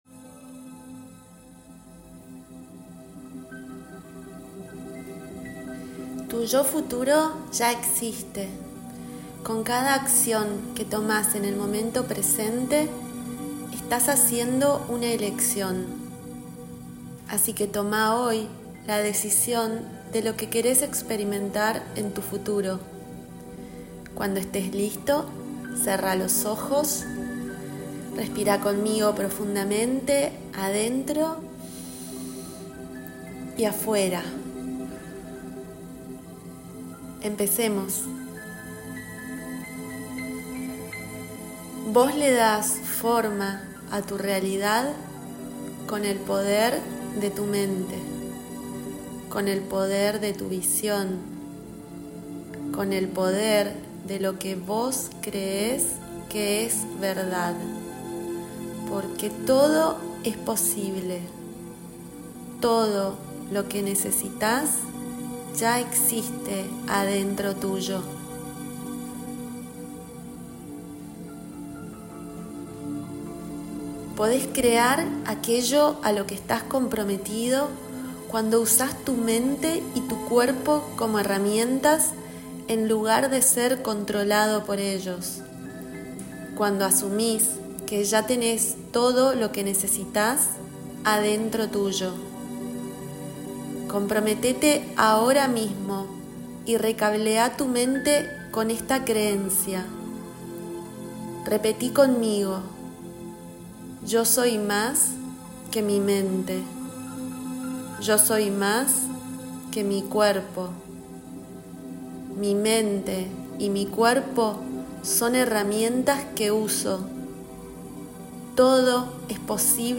MEDITACIÓN GUIADA 1 2024